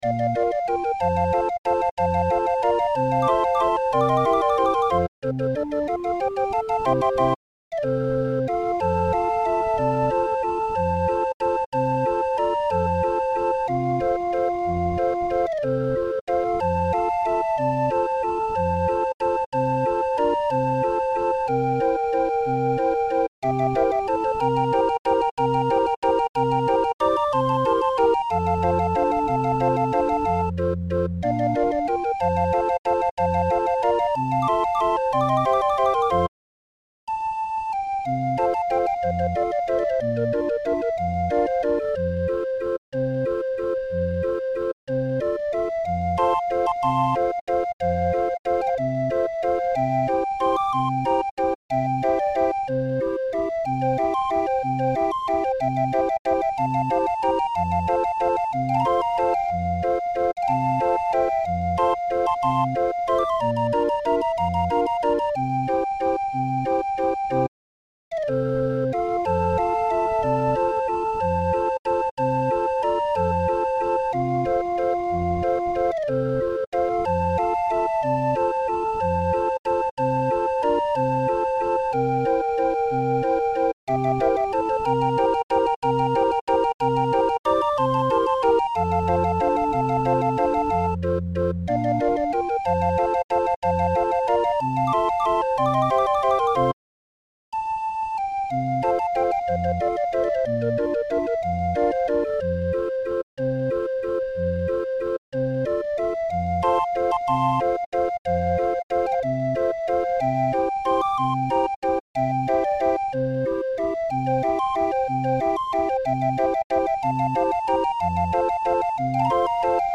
Musikrolle 20-er Raffin